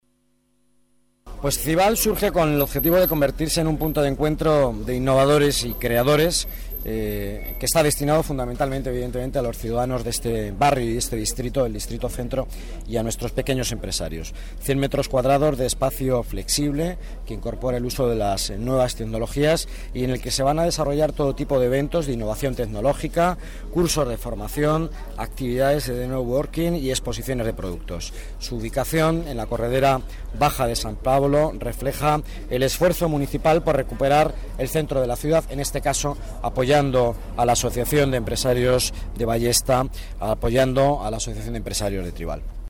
Nueva ventana:Declaraciones del delegado de Economía, Empleo y Participación Ciudadana, Miguel Ángel Villanueva